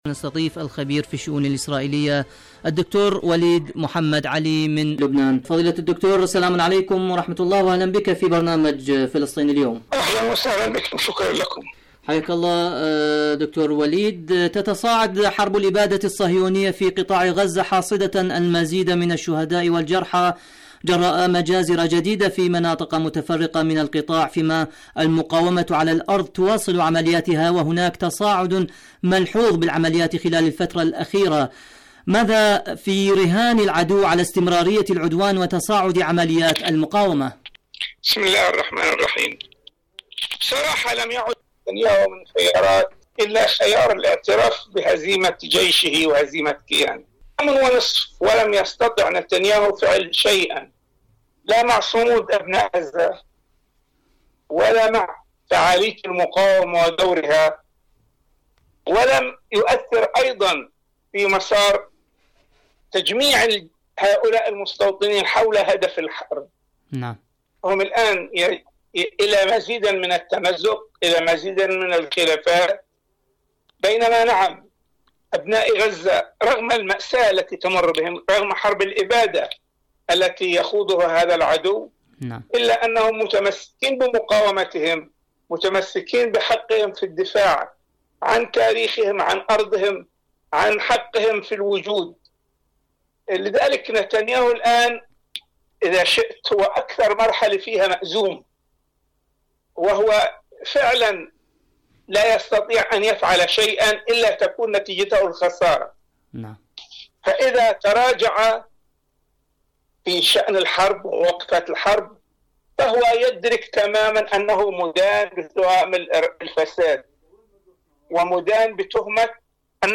الكيان ورسائل المقاومة في الميدان.. مقابلة